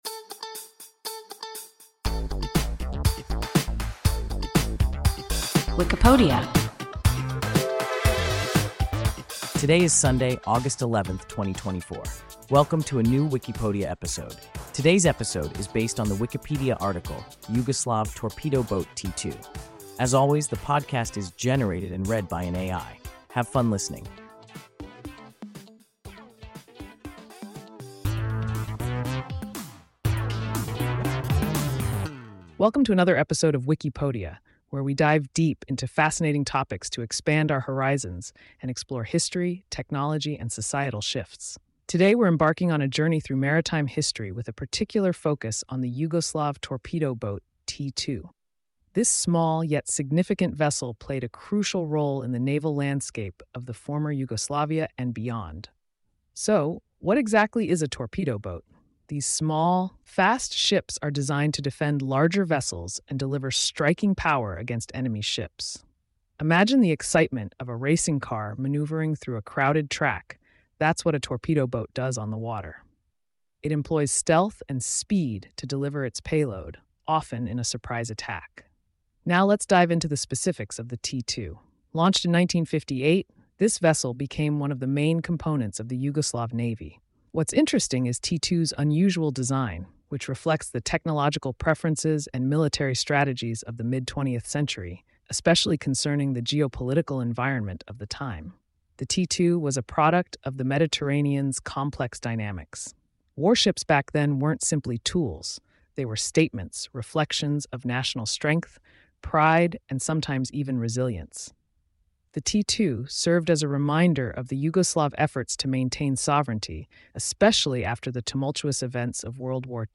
Yugoslav torpedo boat T2 – WIKIPODIA – ein KI Podcast